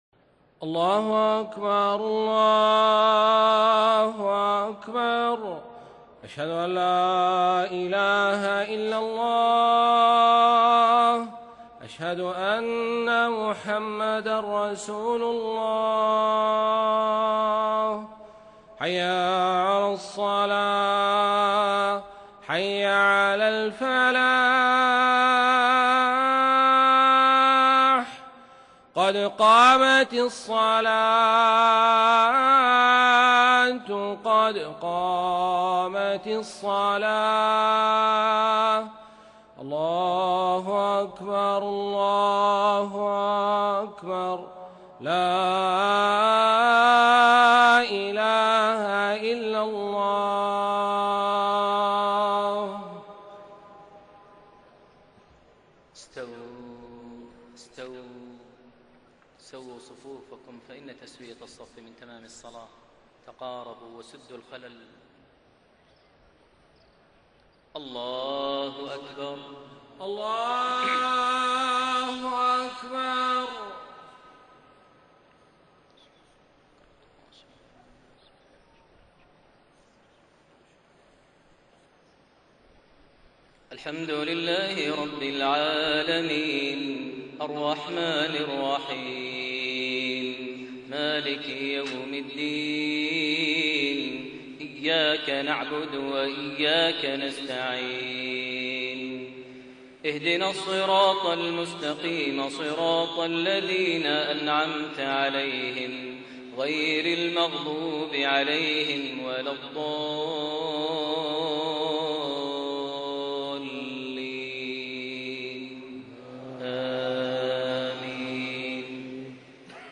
صلاة المغرب 24 ذو القعدة 1432هـ سورة الفجر > 1432 هـ > الفروض - تلاوات ماهر المعيقلي